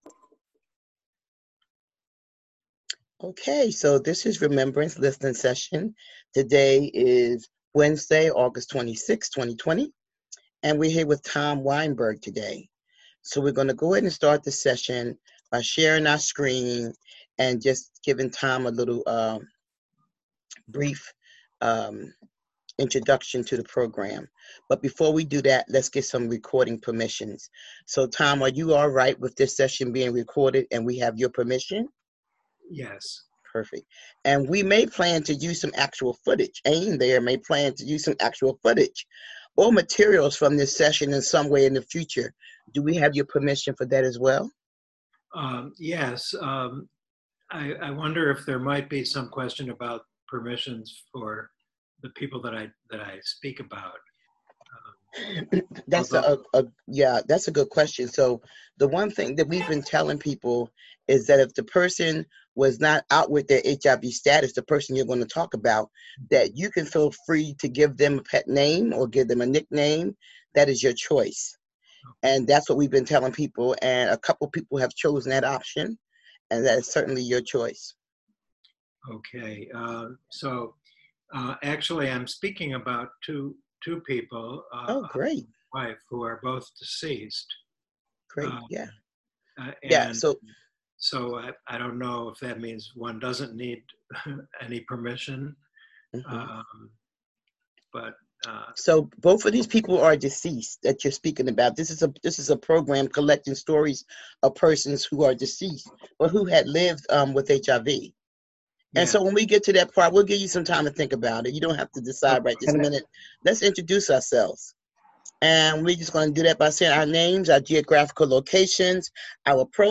Interviewer Remembrance